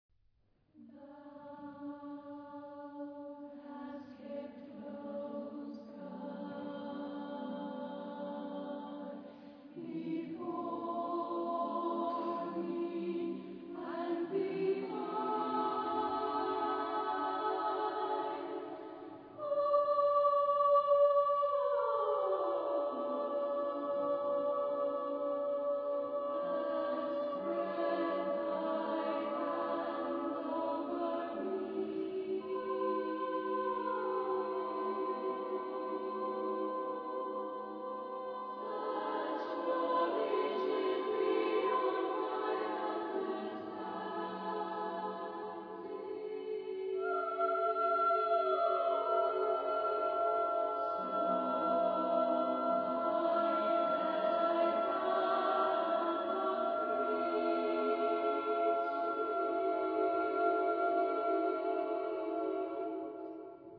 Genre-Style-Form: Sacred ; Motet
Mood of the piece: sustained ; expressive
Type of Choir: SMA (div.)  (3 women voices )
Soloist(s): Soprane (4) / Alto (6)  (10 soloist(s))
Tonality: free tonality